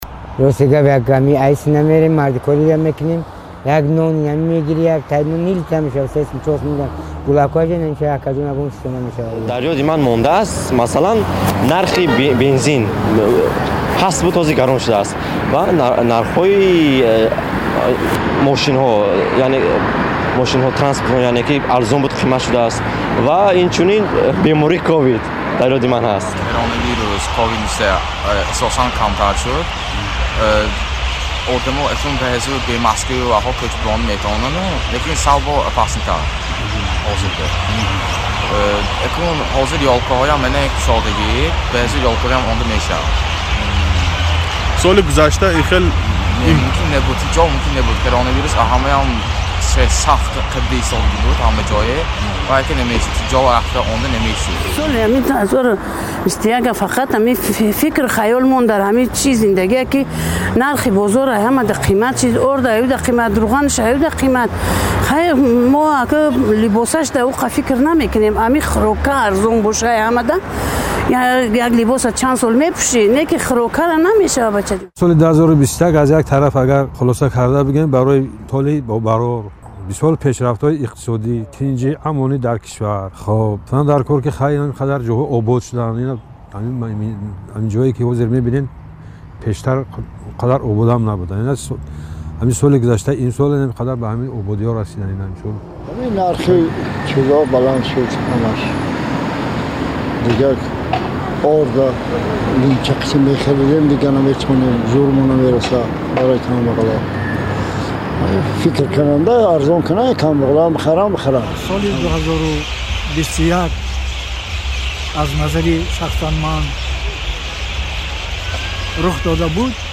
Ба садои теъдоде аз шаҳрвандони Тоҷикистон дар бораи ҳаводиси соли 2021 дар Тоҷикистон гӯш диҳед: